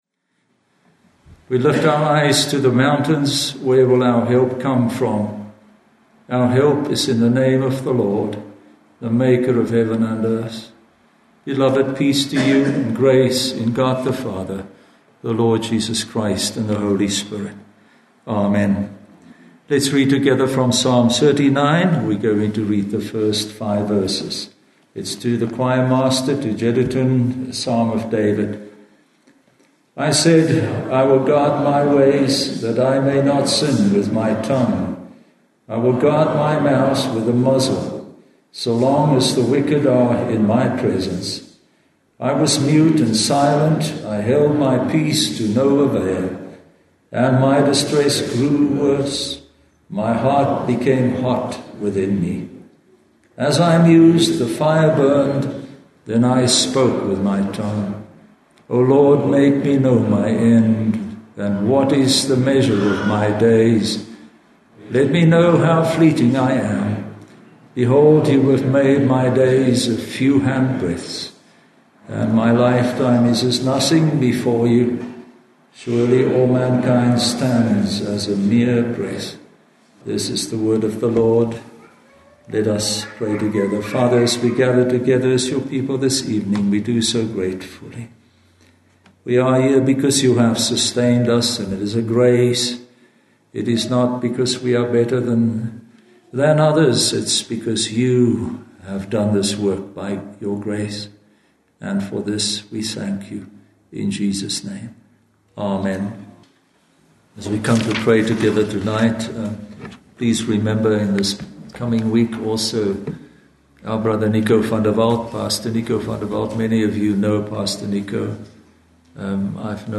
(ESV) Series: Sermon on the Mount